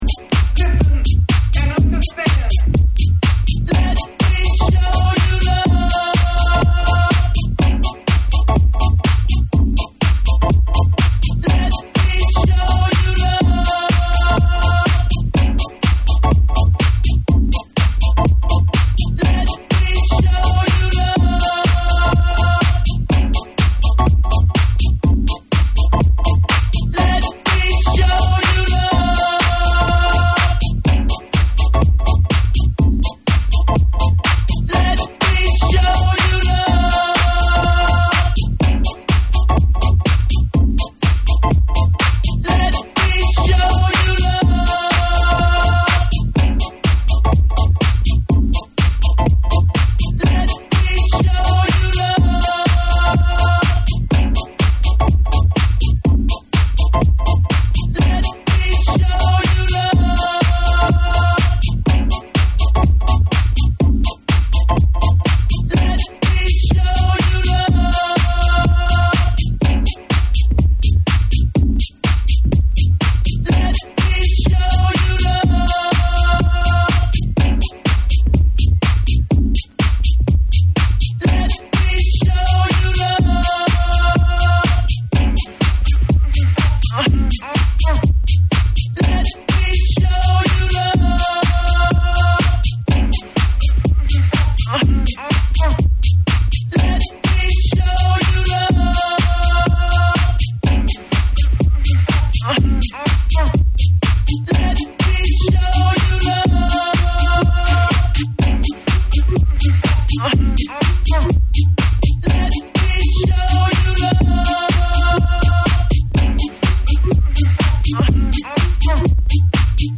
ハウス・クラシック
TECHNO & HOUSE / RE-EDIT / MASH UP